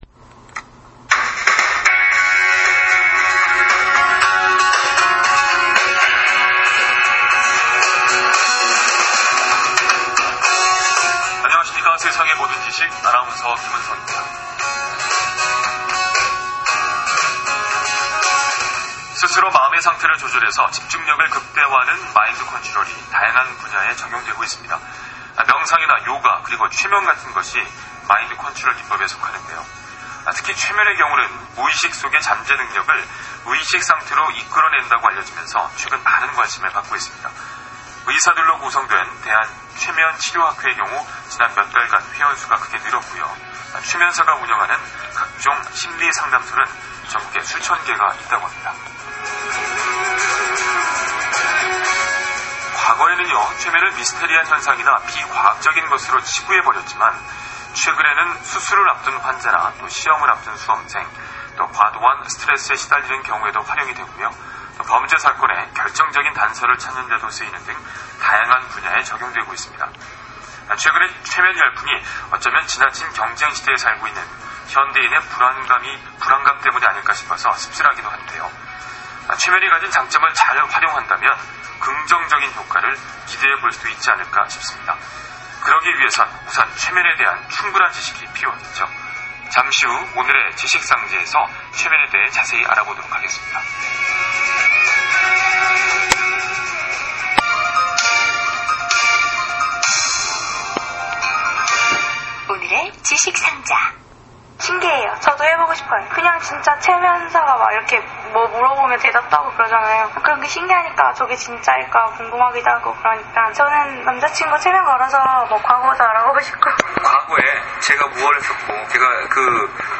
KBS 라디오 생방송 출연!